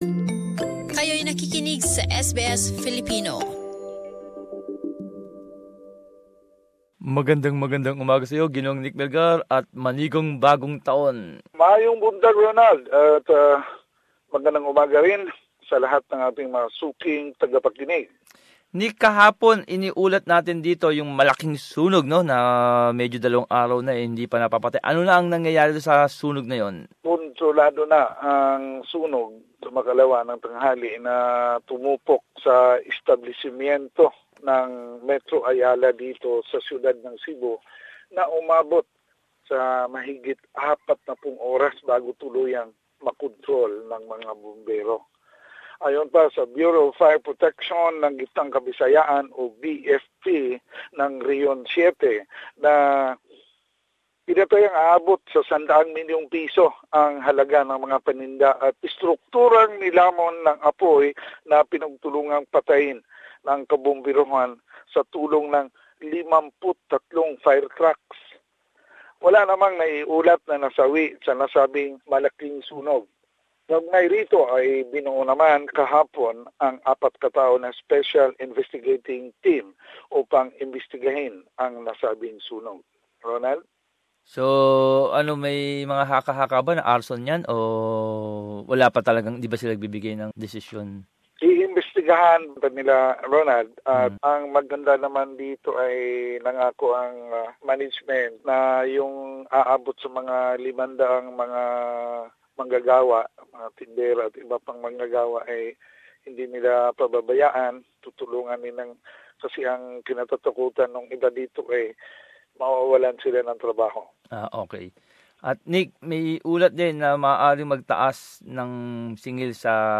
Summary of latest news from the Visayas